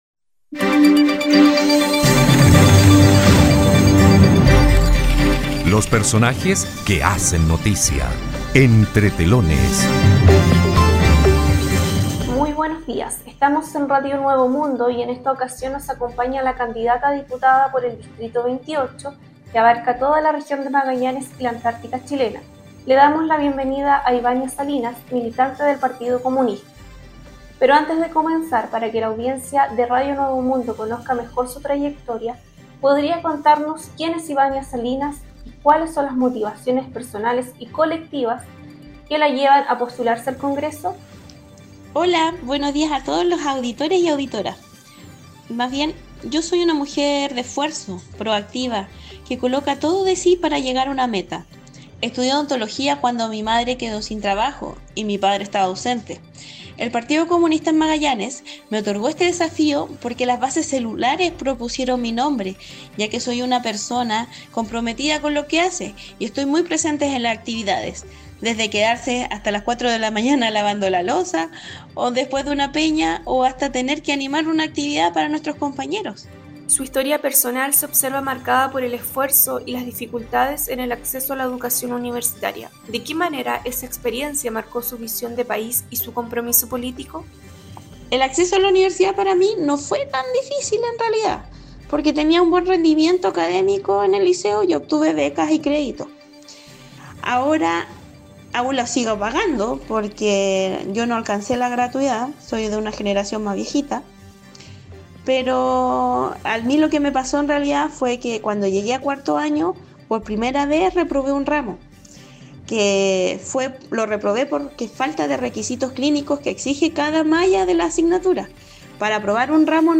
En la entrevista